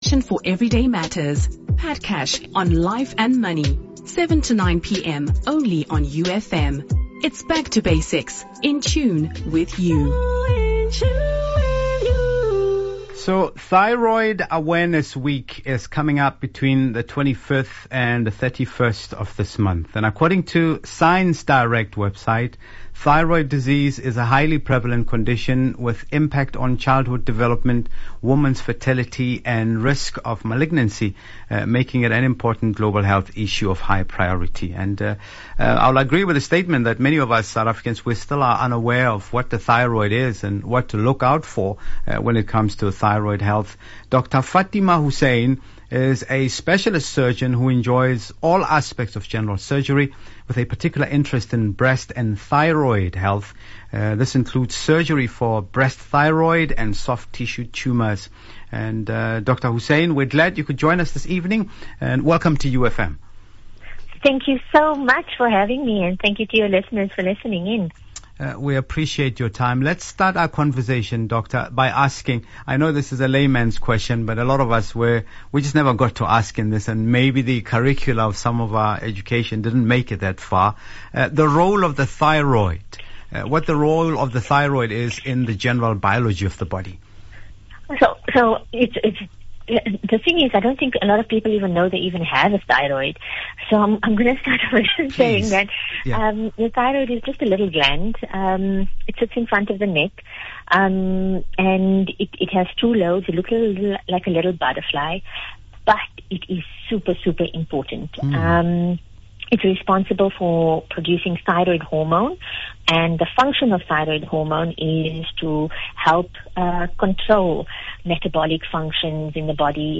With kind permission from YouFM, the full interview clips is available to listen to: